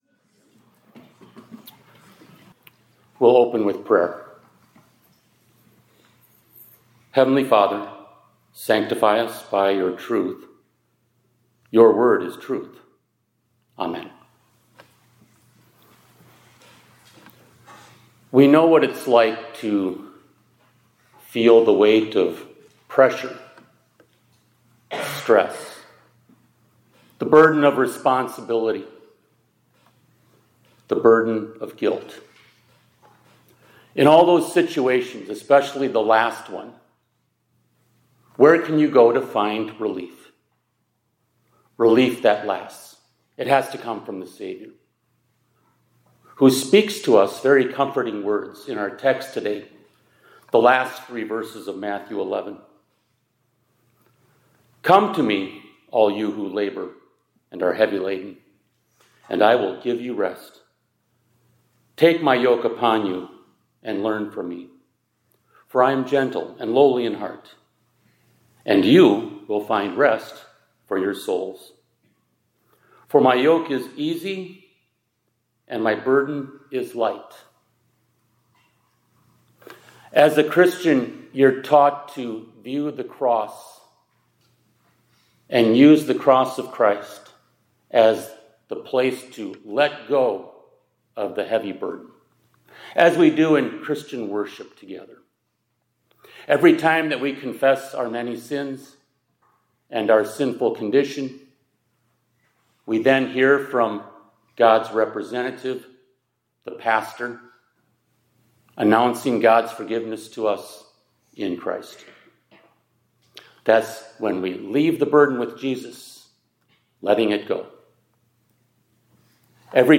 2026-04-01 ILC Chapel — The Yoke of Our Rest is His Word